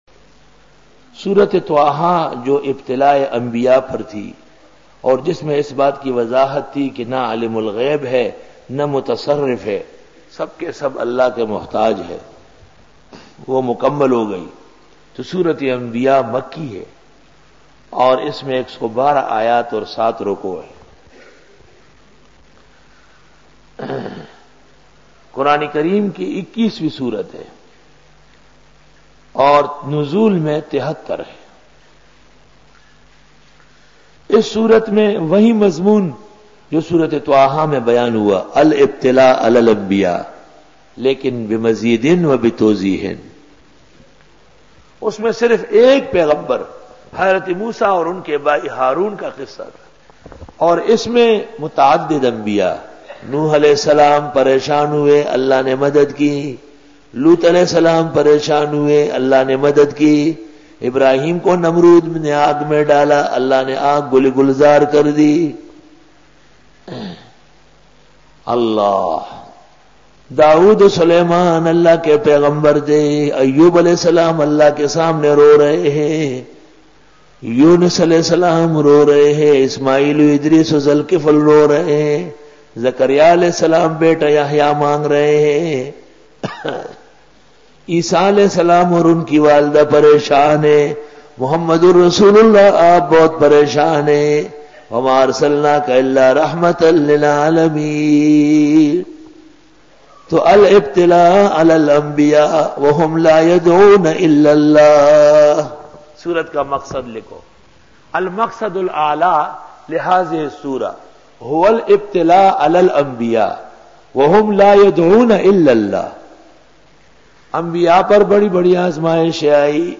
Dora-e-Tafseer 2001